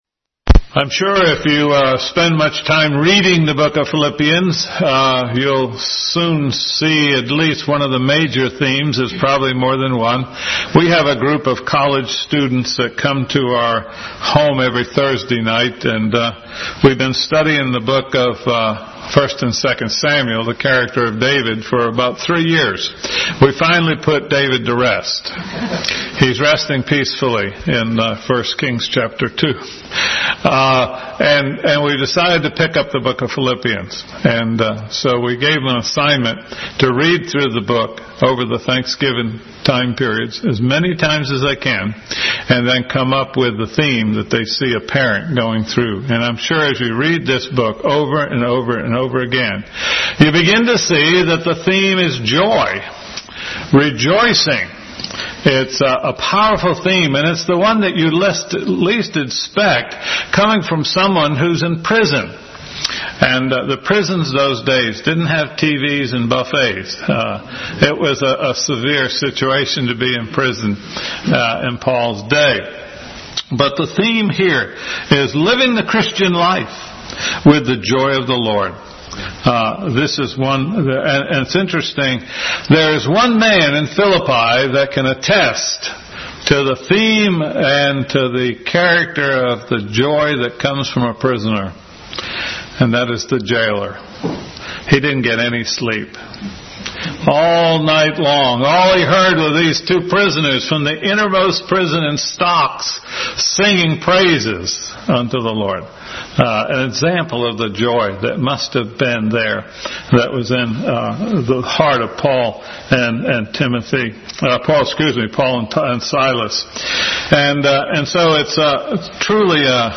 Bible Text: Philippians 1:1-11, 2:2, 3:1, 4:1-4 | Adult Sunday School. Introduction to Pilippians.